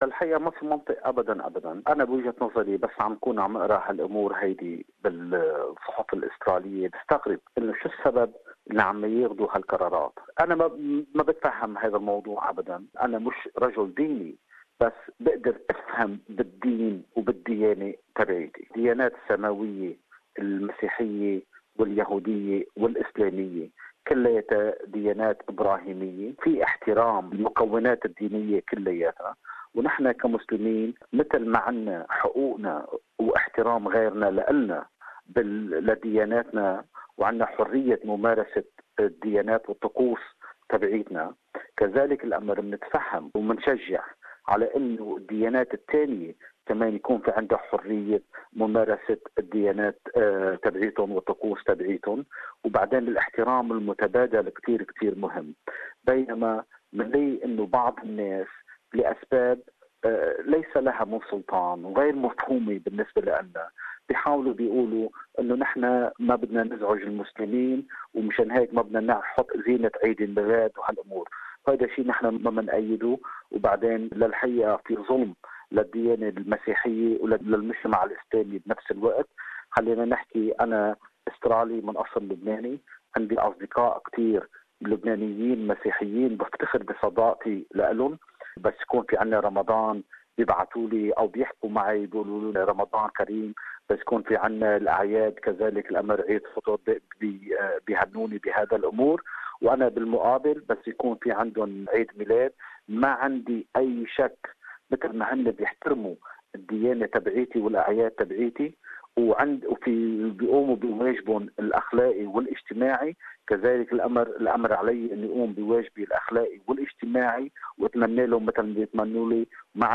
MUSLIM leaders and community members have ridiculed moves to ban Christmas for fear of offending non-Christians. More in this interview